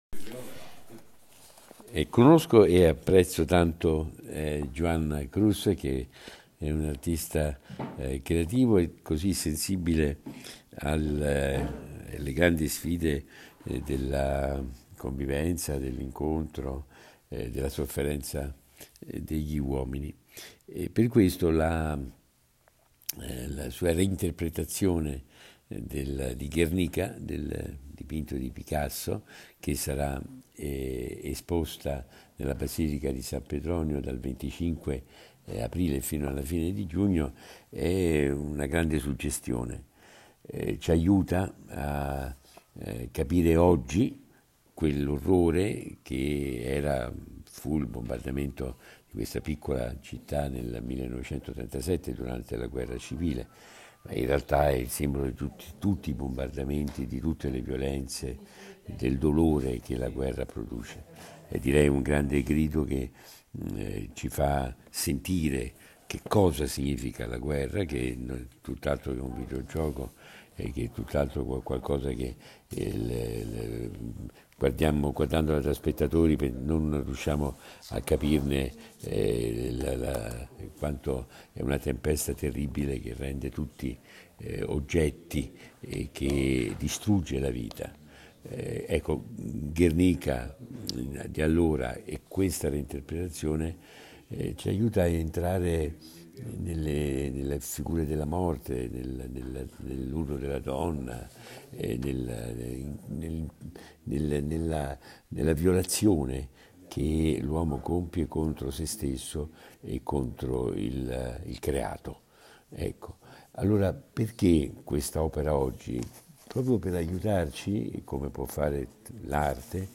Messaggio Arcivescovo Zuppi - Teleimpianti
L'Arcivescovo Matteo Zuppi commenta l'opera.